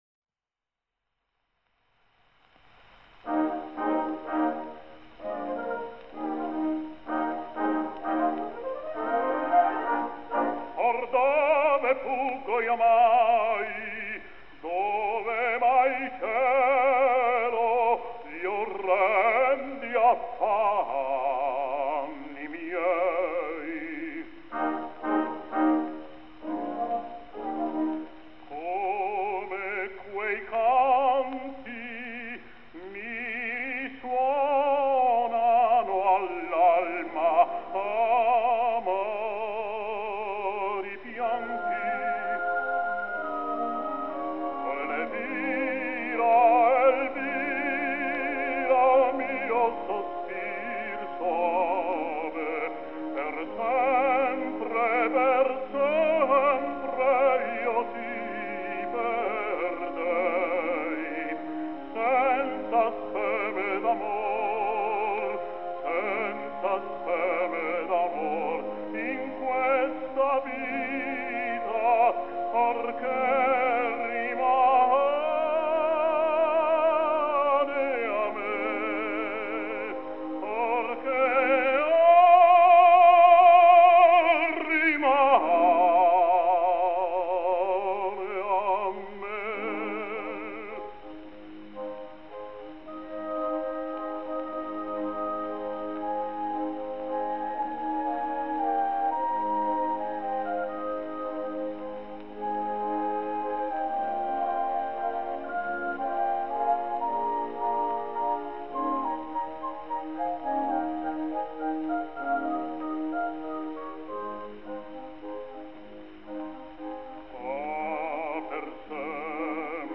И еще один день рождения - выдающегося итальянского баритона Джузеппе Де Лука (1876-1950)!!!